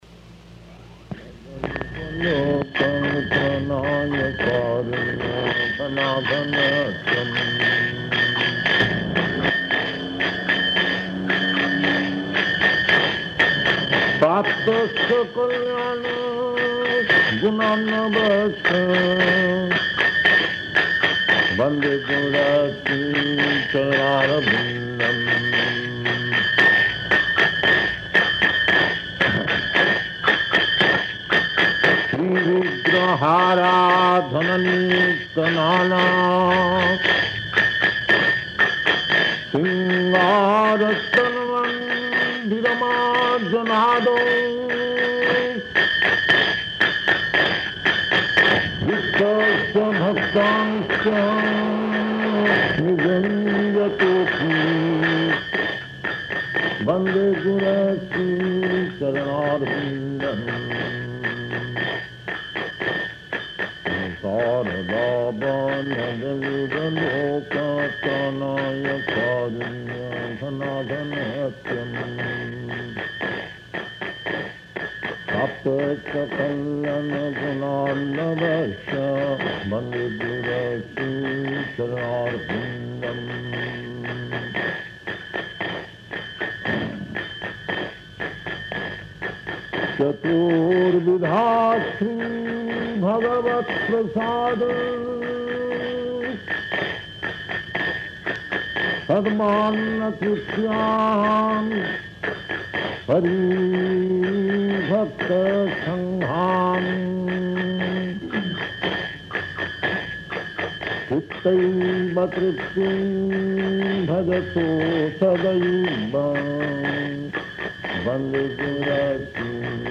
Type: Purport
Location: Montreal